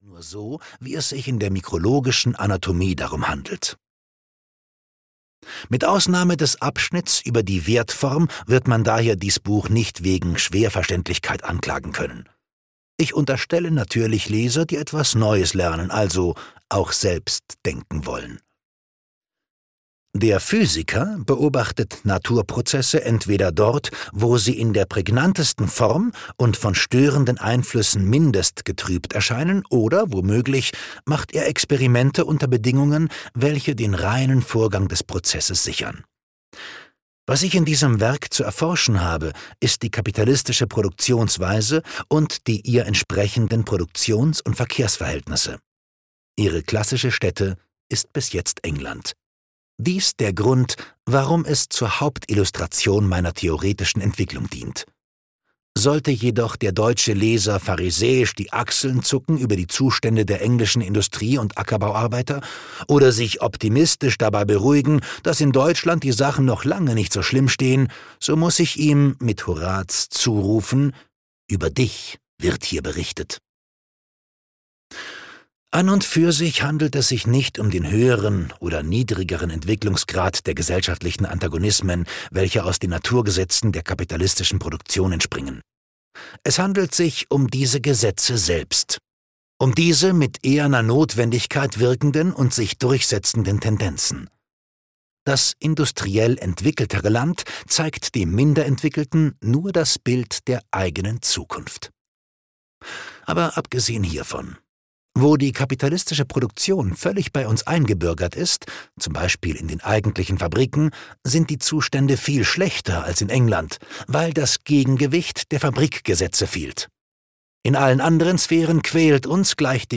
Das Kapital - Karl Marx - Hörbuch